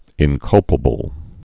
(ĭn-kŭlpə-bəl)